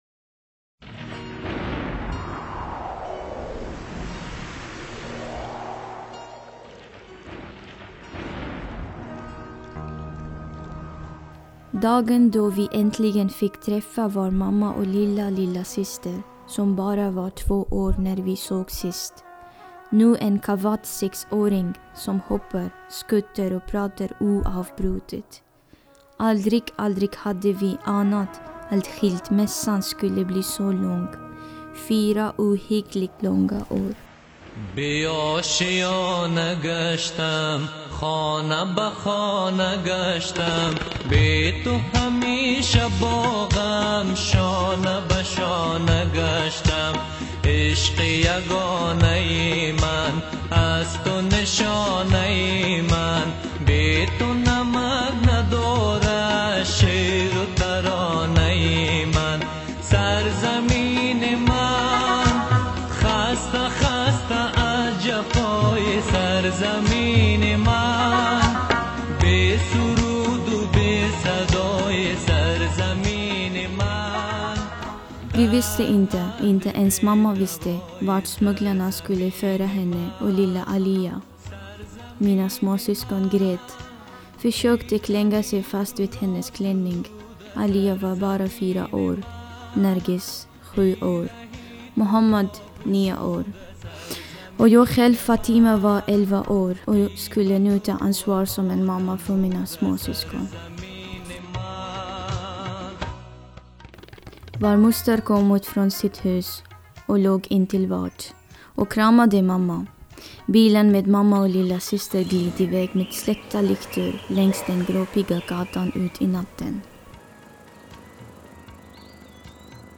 En ung hazar från Afganistan berättar om hur hon fick ta hand om sina småsyskon i pakistan då hennes pappa försvann och mamman flydde till Sverige med hennes lillasyster.